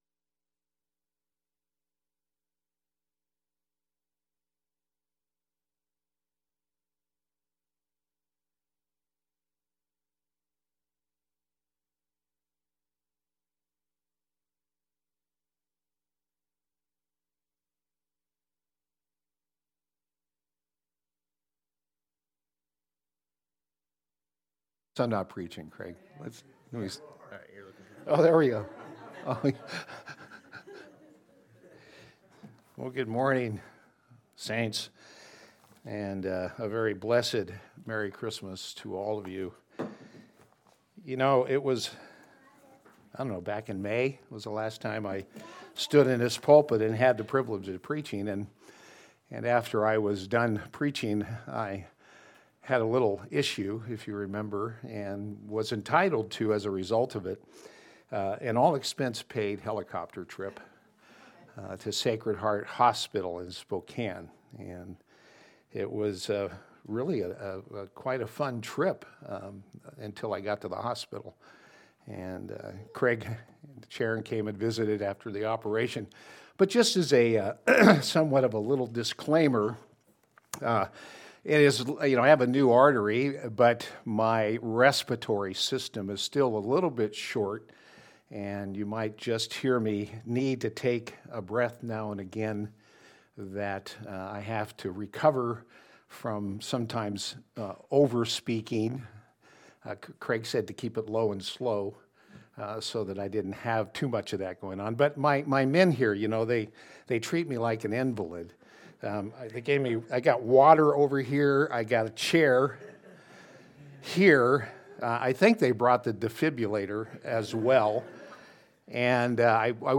John 20:30-31 Service Type: Sunday Service « “2024 Christmas Eve Service